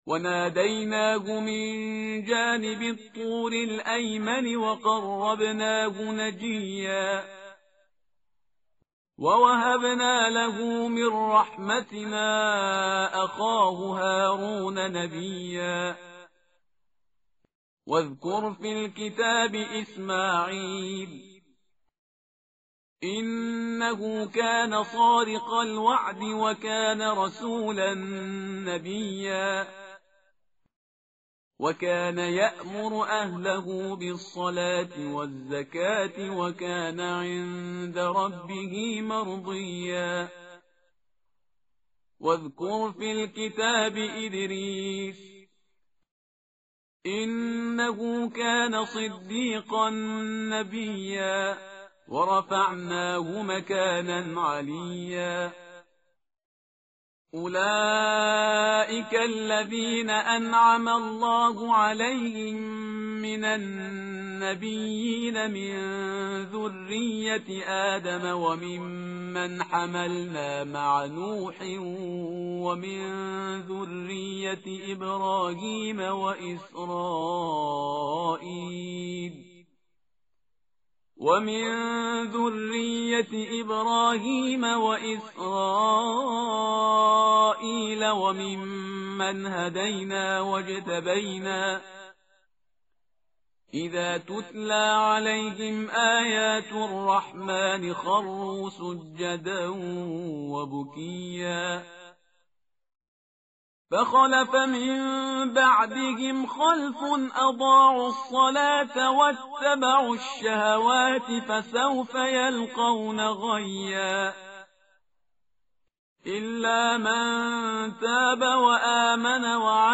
tartil_parhizgar_page_309.mp3